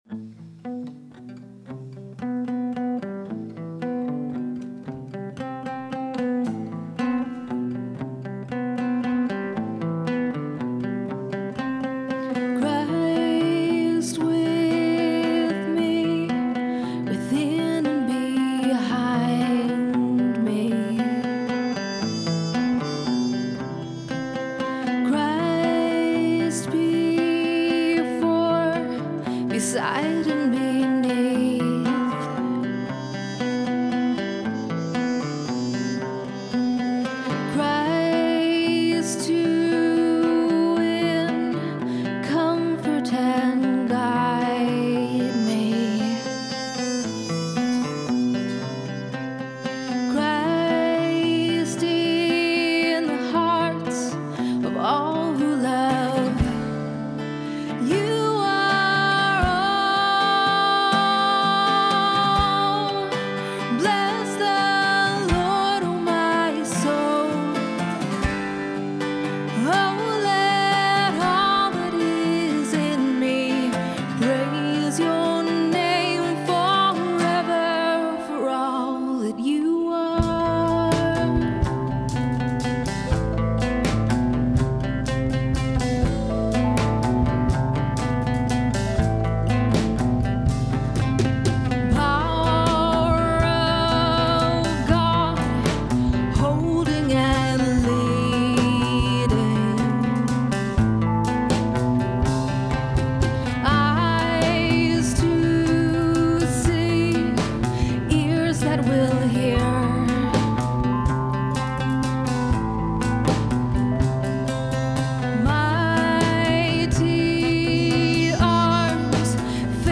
Performed live at Terra Nova - Troy on 2/21/10.